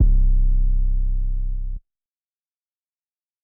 Sauced Spinz 808.wav